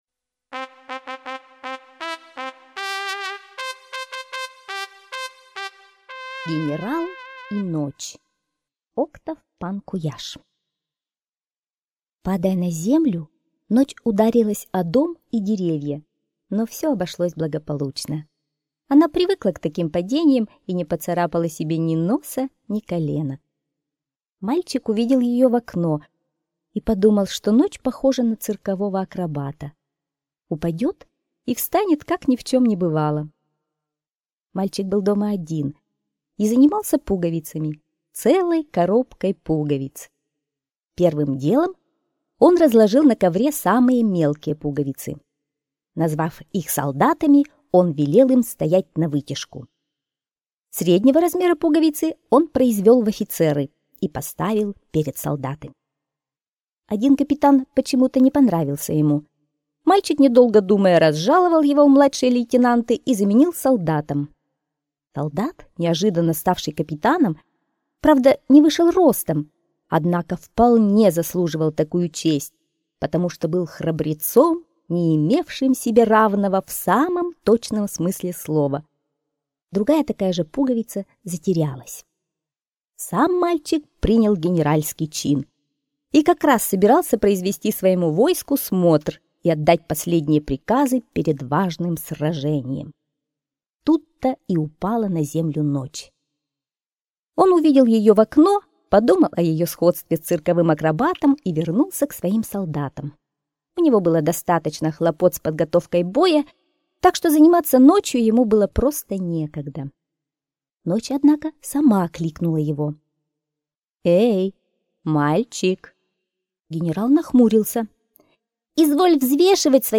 Генерал и ночь - аудиосказка Панку-Яшь О. Один мальчик вечером играл с пуговицами.